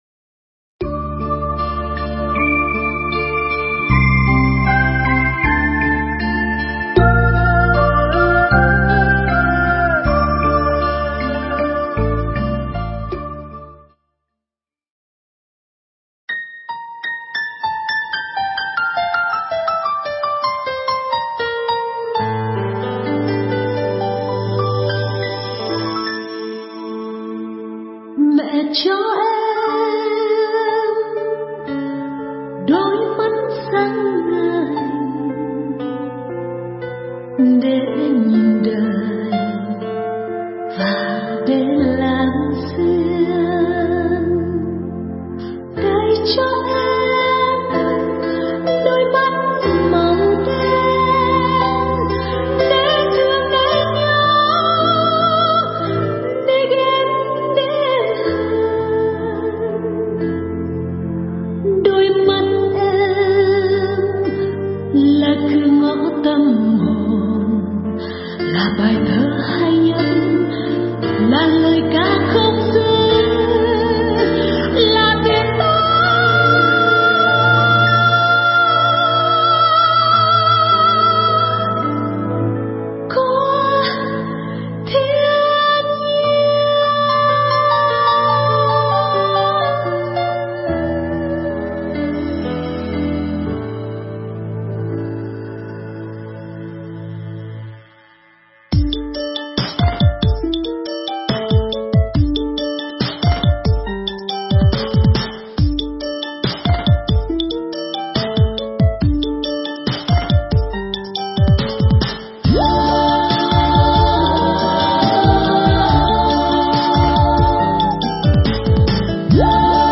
Nghe Mp3 thuyết pháp Đau Thương Làng Mù